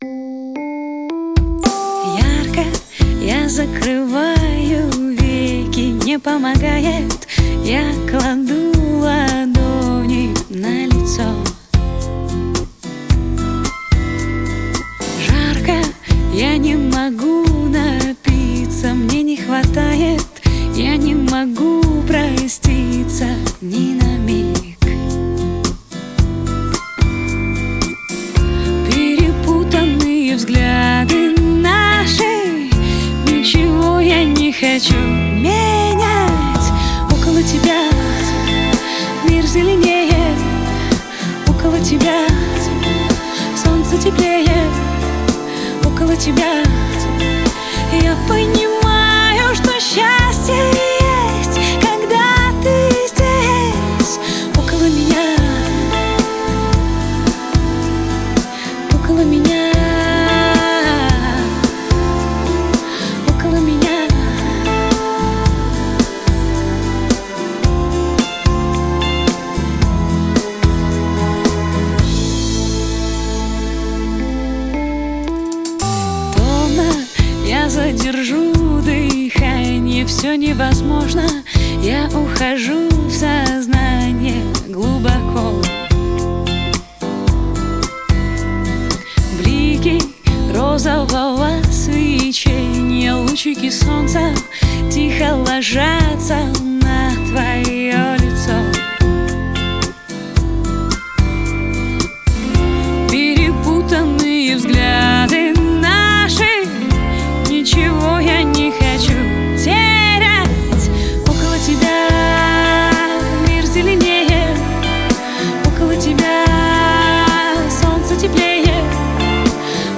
Жанр: Только качественная POP музыка